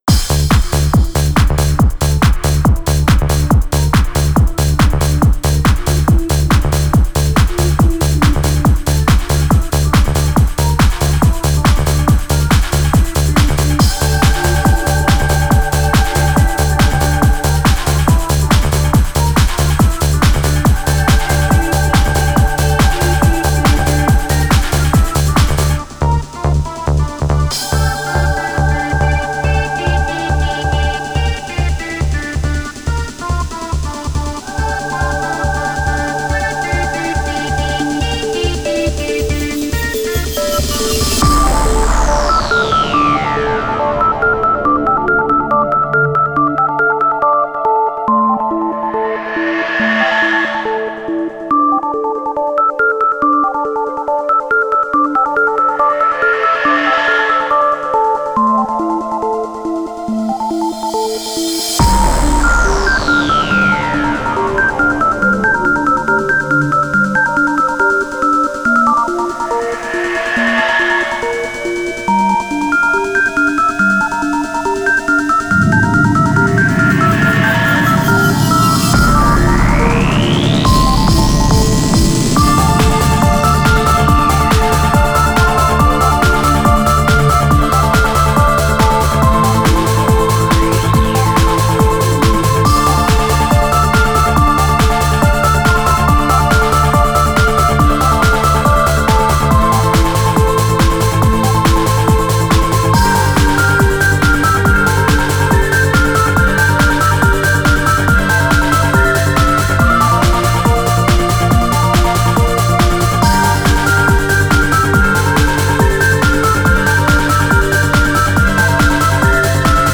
• Ремикс